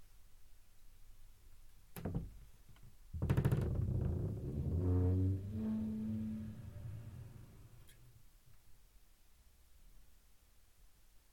Opens/Right Door wardrobe Door
Duration - 11s Environment - Bedroom, a lot of absorption with bed linen and other furnishings.